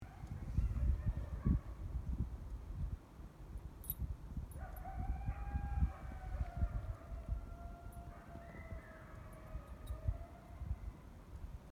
Here is a howl I heard two days after Dad arrived at his old home: this would be him and the two youngsters who had been living there. They are obviously still one family unit — a reconfigured family unit.
Howls: Dad and two youngsters upon his return: